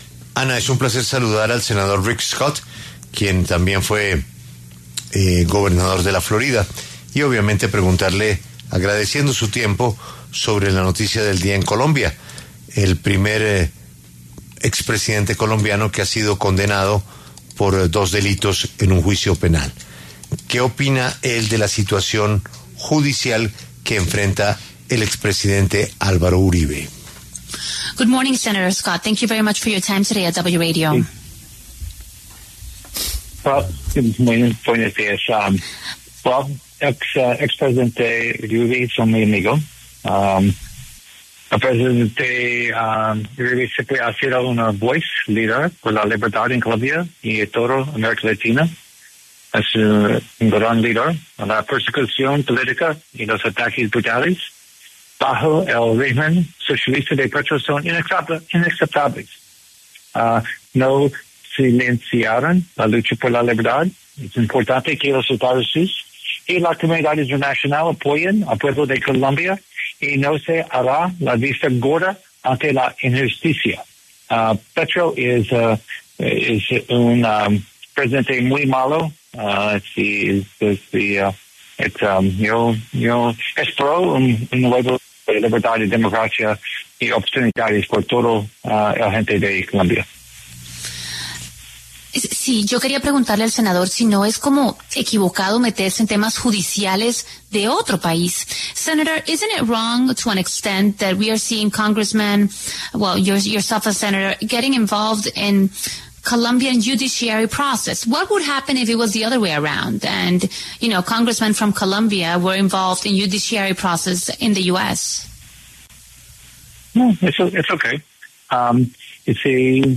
El senador republicano Rick Scott se pronunció en La W sobre el fallo judicial contra el expresidente Álvaro Uribe.
Para hablar sobre el tema, pasó por los micrófonos de La W el senador republicano Rick Scott, quien representa al estado de Florida desde 2019.